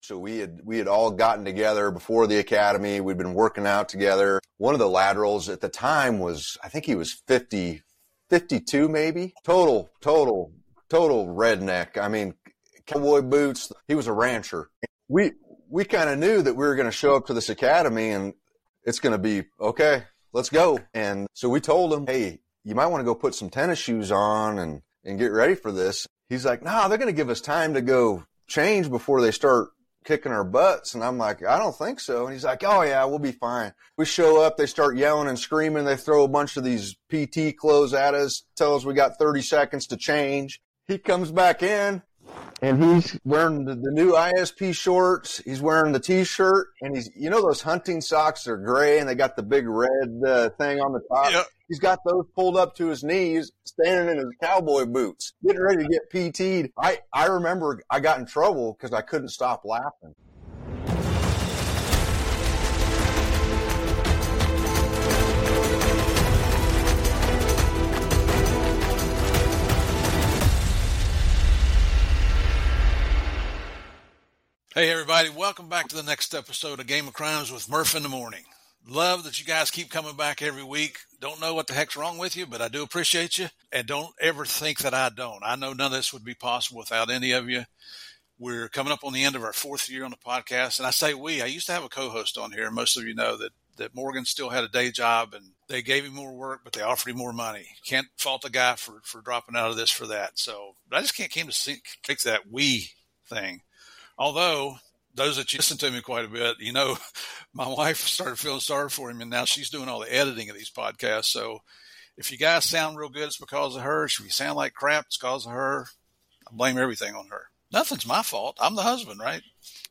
This episode offers a candid look at the realities of law enforcement work, mixing serious insights with lighthearted, humorous crime stories.